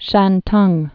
(shăntŭng, shäntng)